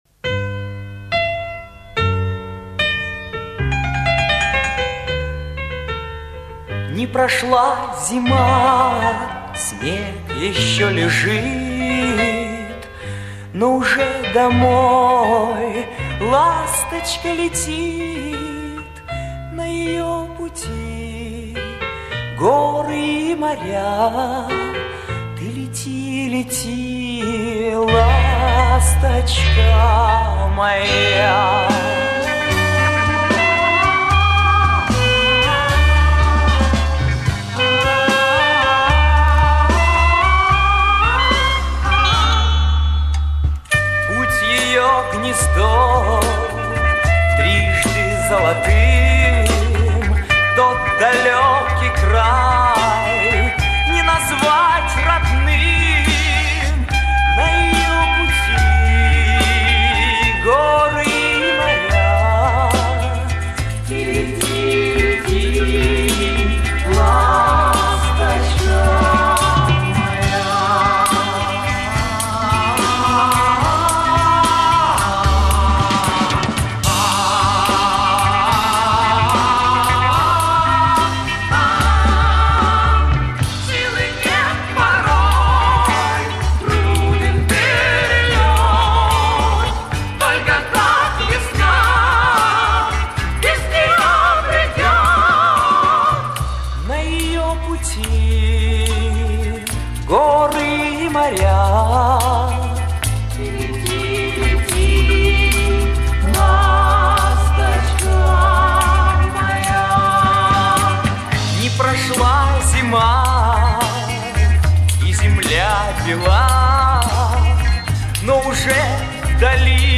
Пластинка с этой песней вышла в 1975 году.